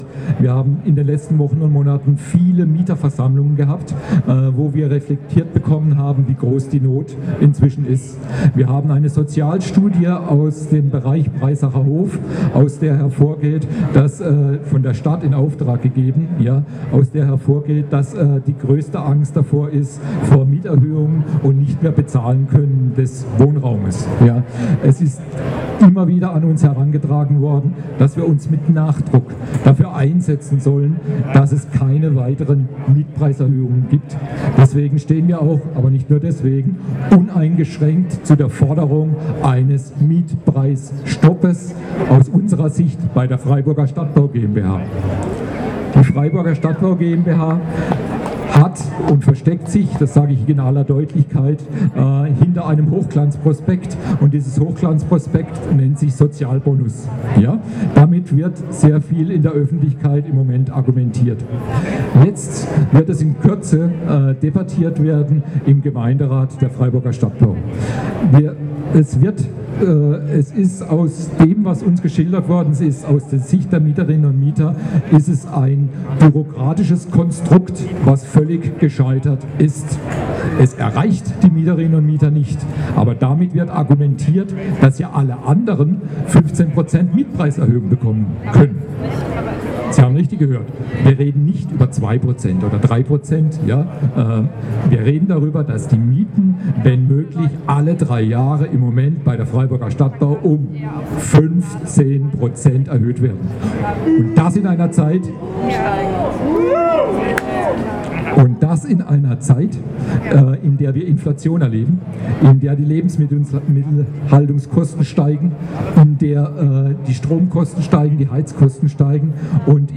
Mietenstopp Aktionstag in Freiburg: Reden u.a. vom Klimacamp, Klimaentscheid, MieterInnen und DGB